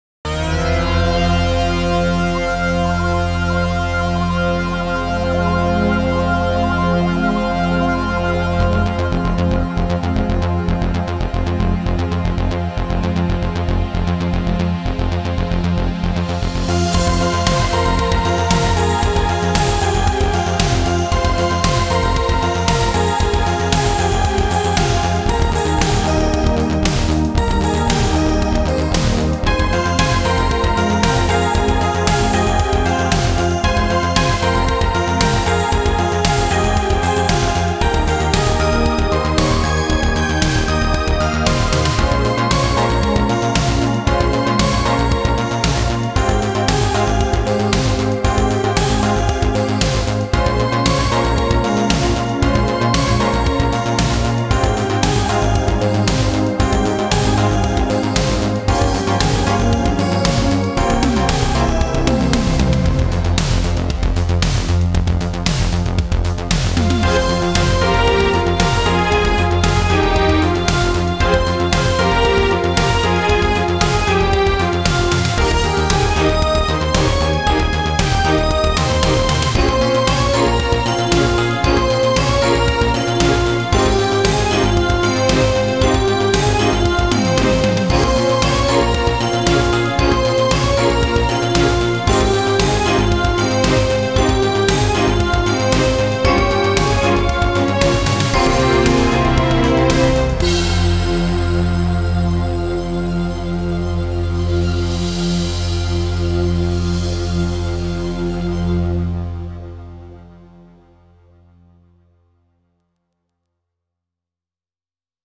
GSi Genuine Sounds is a collection of fine sampled instruments based on a very efficient and unique proprietary sound engine made by GSi and called WLF - Wavetable Linear Format.